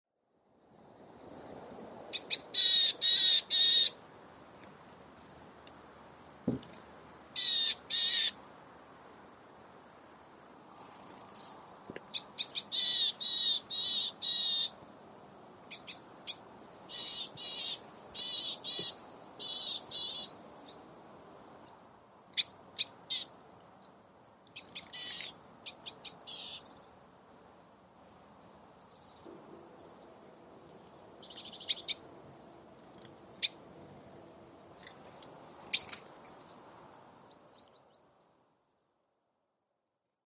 lyhyitä ääniä, yksittäin tai sarjana toistettuja. Joskus voit kuulla näinkin aktiivisen äänisarjan, kuin konekirjoitusta tai puhelimen hälytysääntä muistuttavan.
hompan_tik.mp3